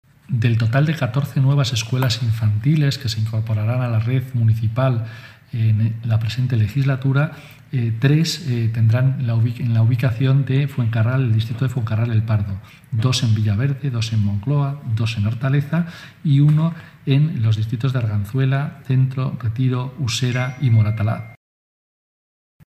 Carlos Sánchez Mato enumera la ubicación de las próximas escuelas infantiles